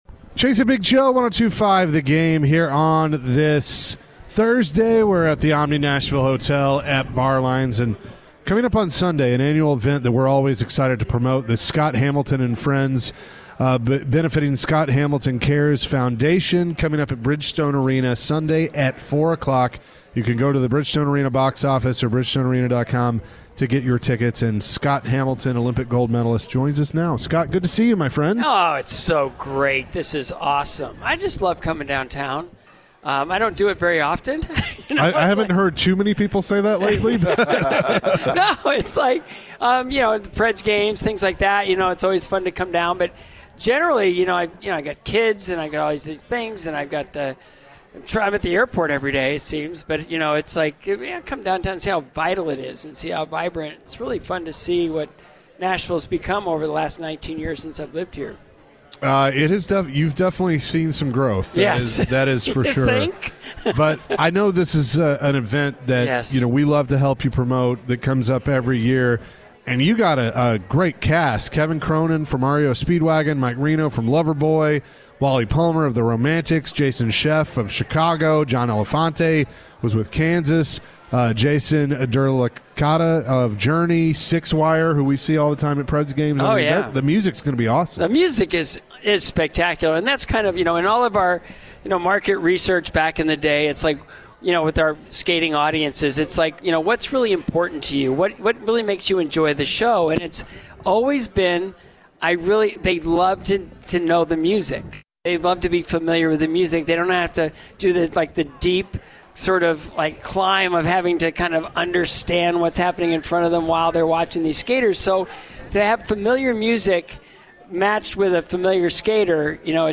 Scott Hamilton joined the show for the full hour. Scott spoke more about his cancer journey. He was asked if he had ever been mad at God for his diagnosis.